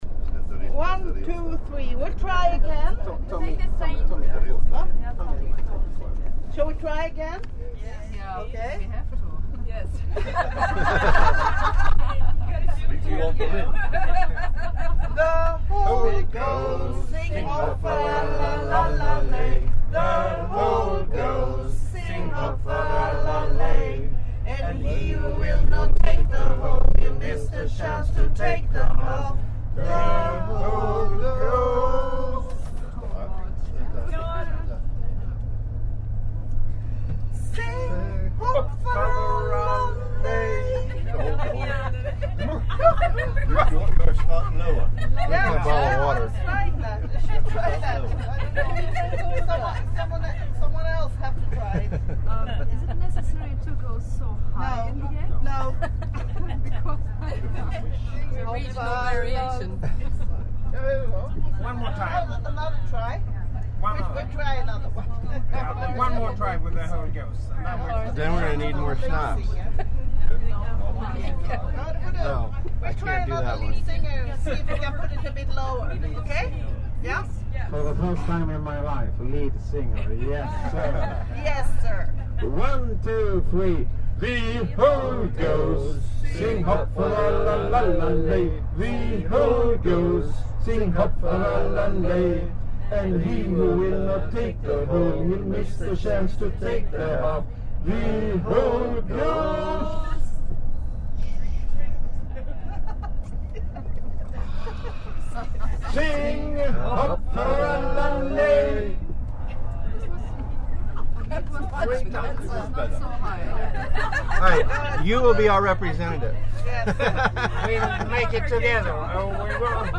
On the bus returning to our hotel we sampled some snaps and practiced some drinking songs for some sort of competition tonight.
IFAJ Meat Wagon Song Practice
ifaj-12-drinking-song.mp3